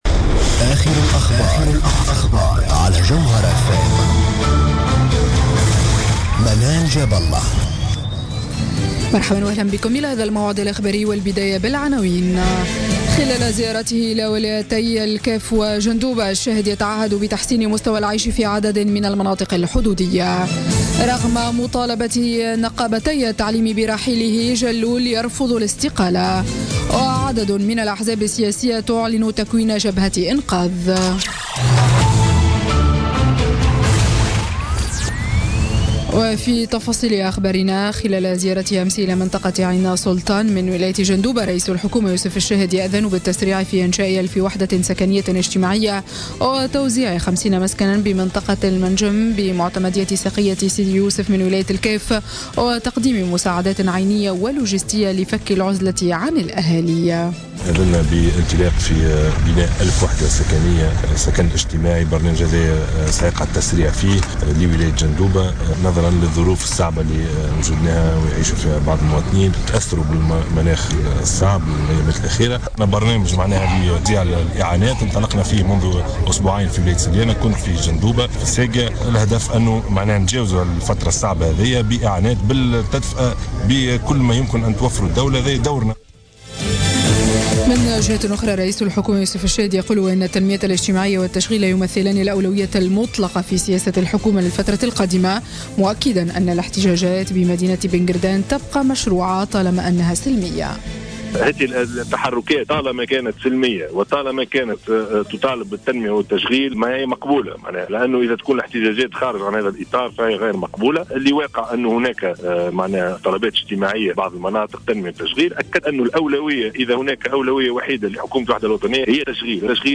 نشرة أخبار منتصف الليل ليوم الجمعة 13 جانفي 2017